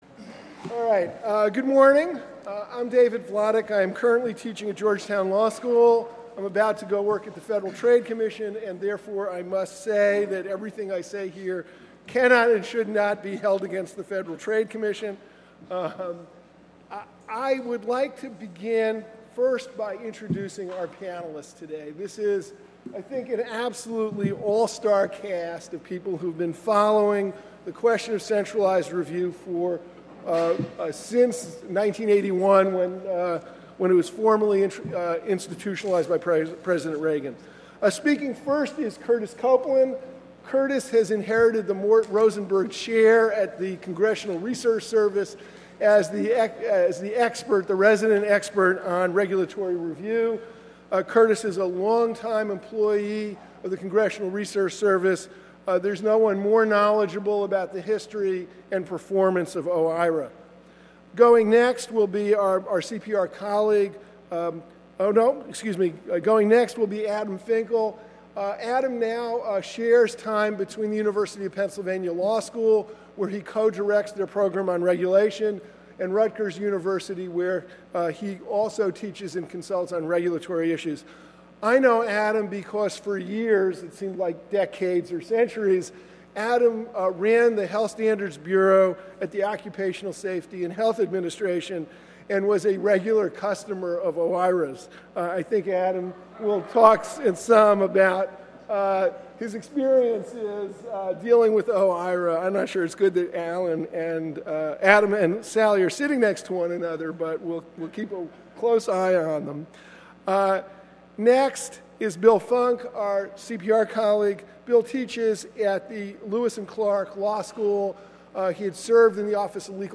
Following are audio recordings of the discussions in mp3 format.